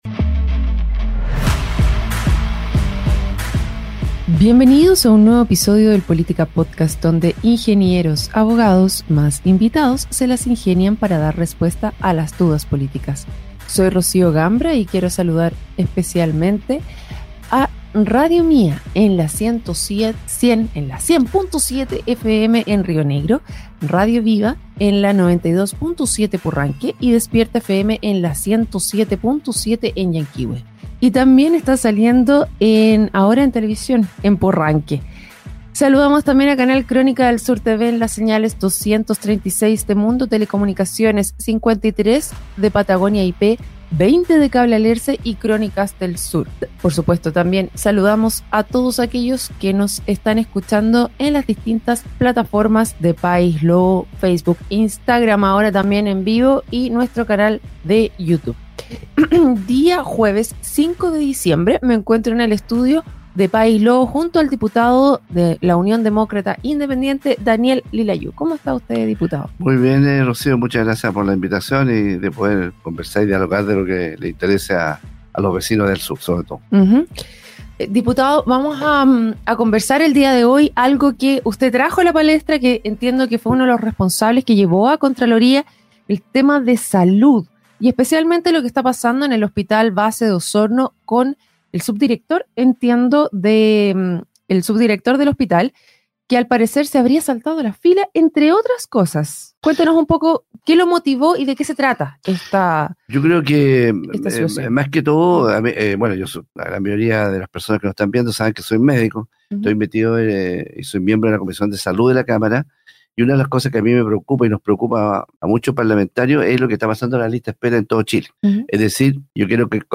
El diputado Daniel Lilayú (UDI) fue invitado al programa Política Podcast, donde abordó la grave crisis en las listas de espera del sistema de salud chileno, con especial énfasis en las denuncias relacionadas al Hospital Base de Osorno.